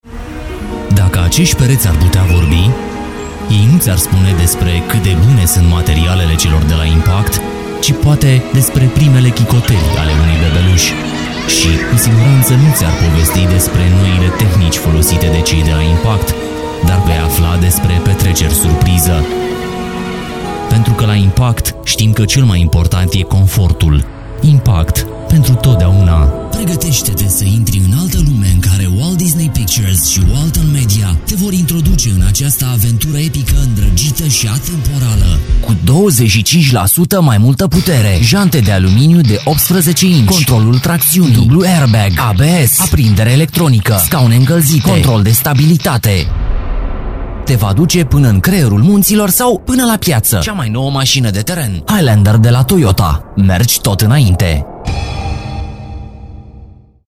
Sprecher rumänisch für TV / Rundfunk / Industrie / Werbung.
Sprechprobe: Industrie (Muttersprache):
Professionell rumanian voice over artist